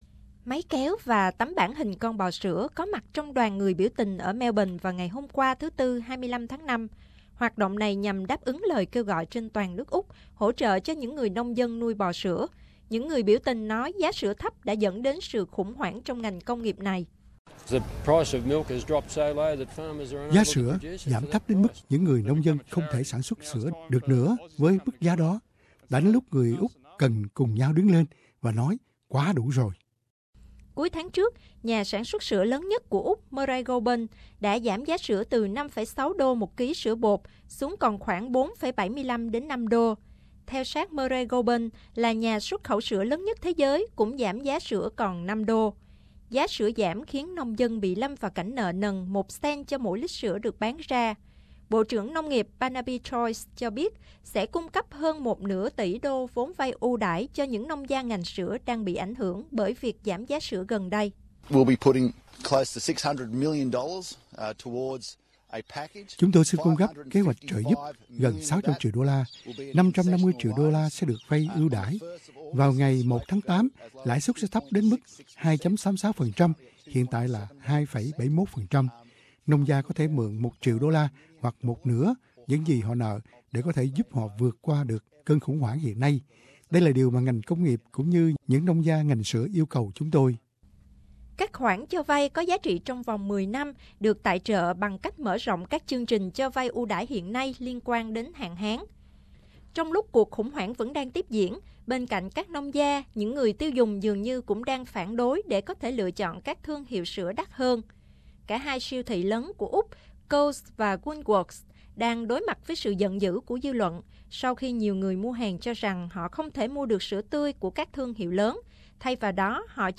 Dairy Farmers rally in Melbourne (SBS World News)